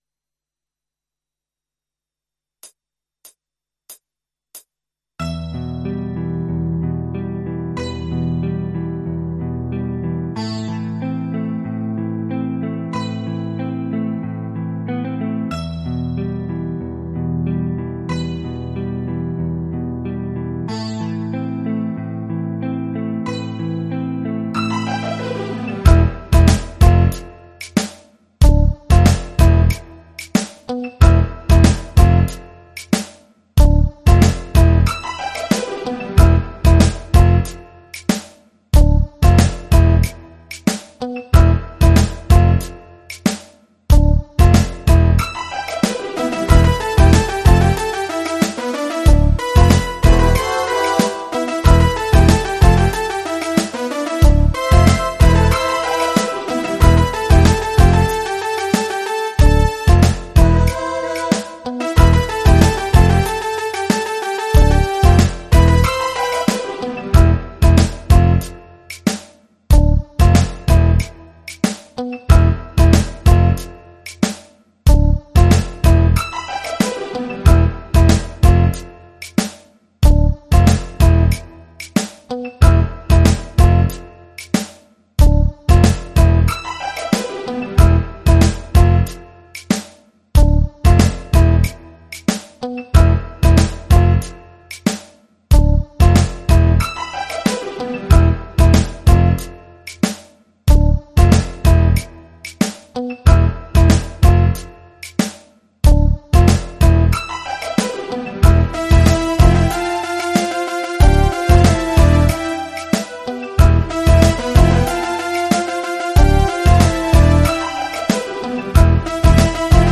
PRO MIDI Karaoke INSTRUMENTAL VERSION